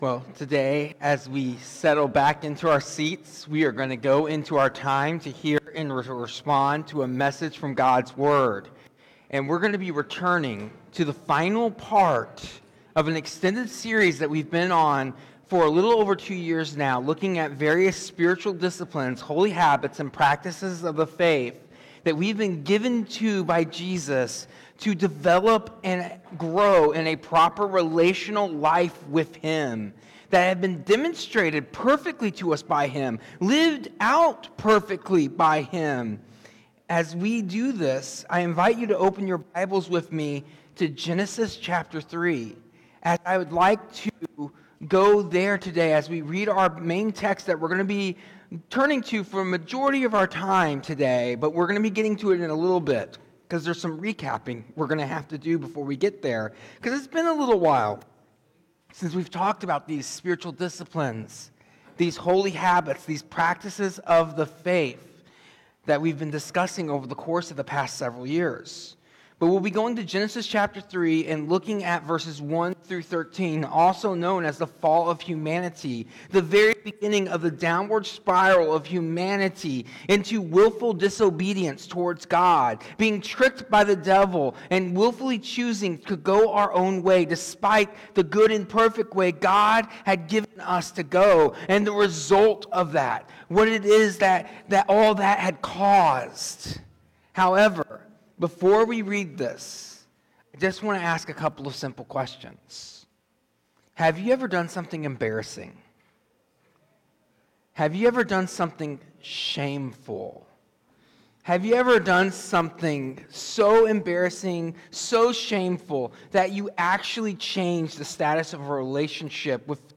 This sermon begins our conclusion of a two-year journey through the Spiritual Disciplines, Holy Habits, and Practices of the Faith by focusing on the often-neglected practice of confession. Using Genesis 3, it explores how sin introduced shame into human experience, fracturing relationships with God, others, and ourselves.